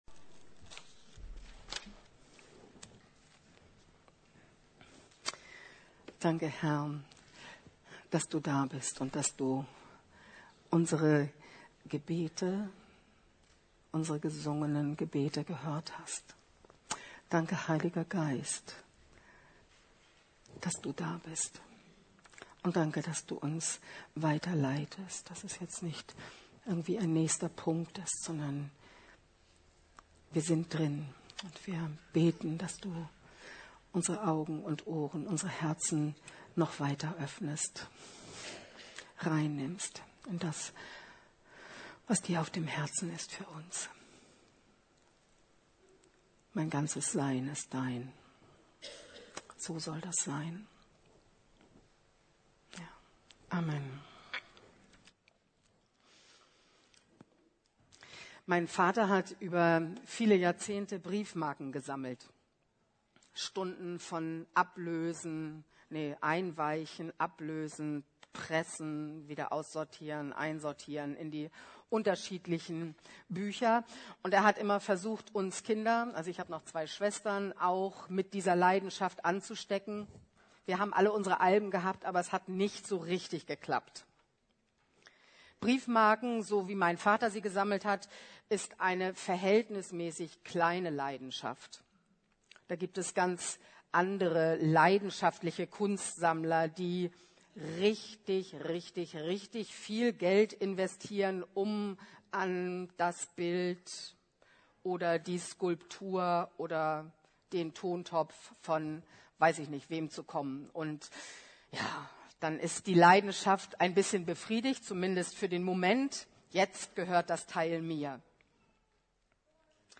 Leidenschaft für Gott, Gott leidenschaftlich. ~ Predigten der LUKAS GEMEINDE Podcast